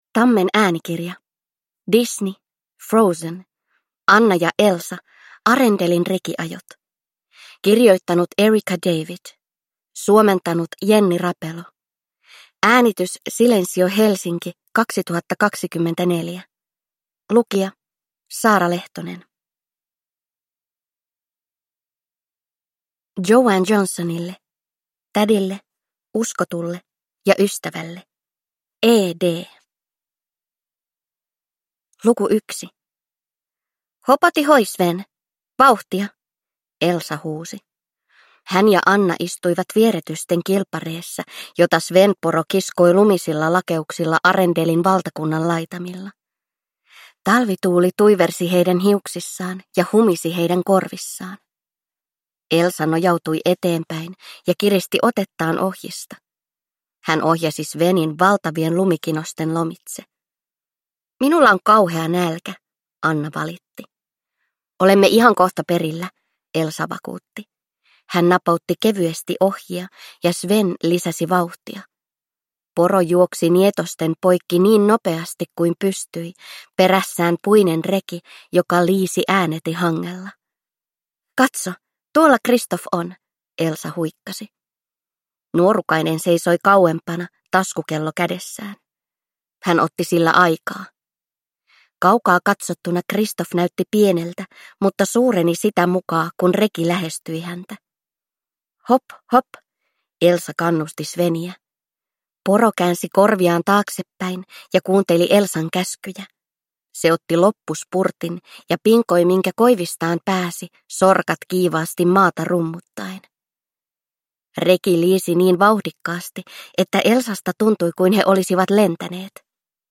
Frozen. Anna & Elsa: Arendelin rekiajot – Ljudbok